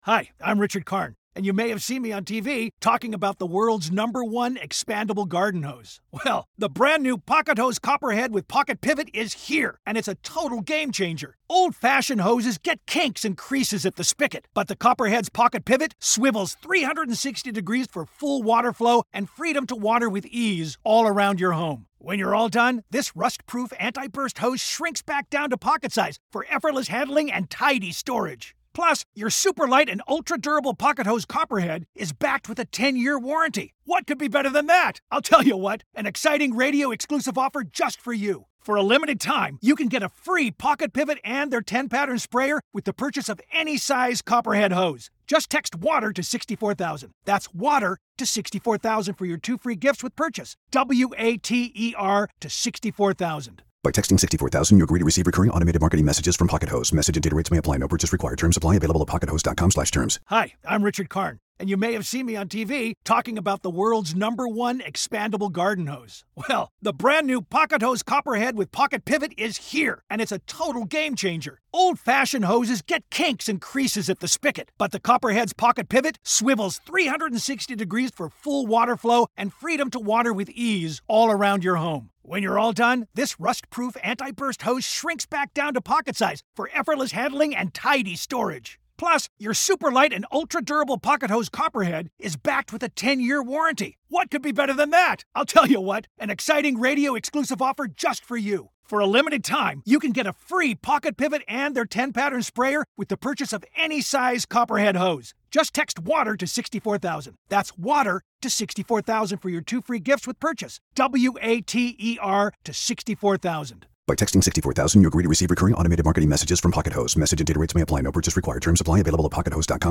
Listen to the full courtroom trial coverage of Alex Murdaugh.